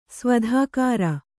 ♪ svadhākāra